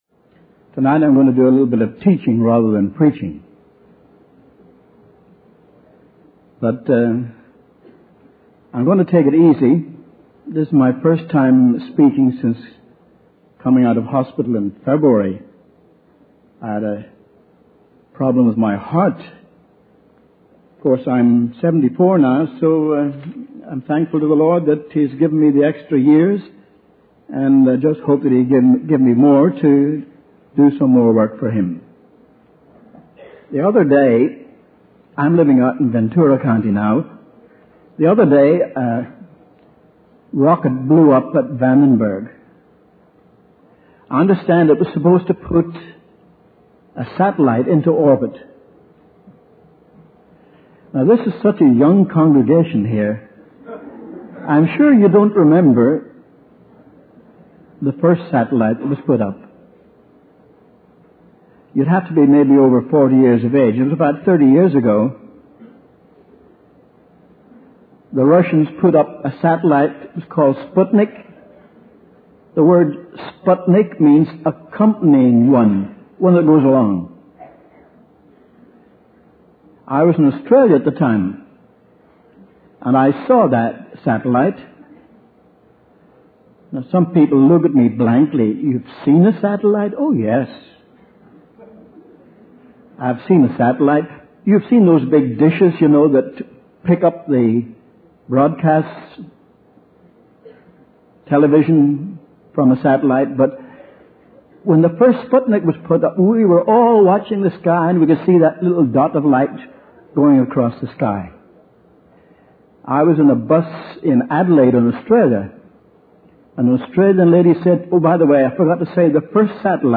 In this sermon, the speaker begins by sharing that he is teaching rather than preaching due to his recent health issues.